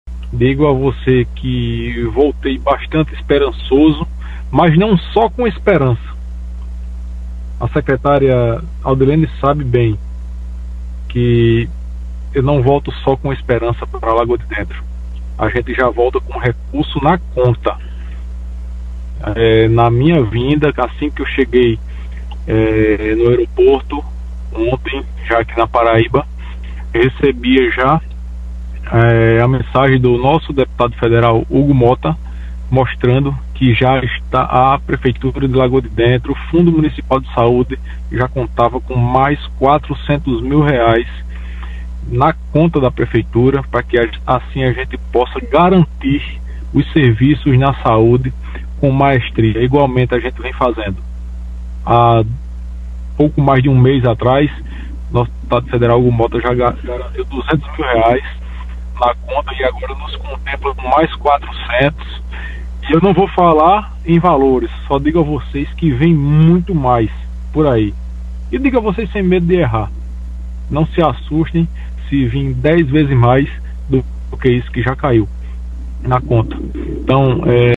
O prefeito Camaf Douglas anunciou no programa de rádio Construindo o nosso futuro desta quinta-feira (17/07) a chegada de R$ 400 mil reais em recursos destinados à saúde do município, fruto de uma importante parceria com o deputado federal Hugo Motta (Republicanos).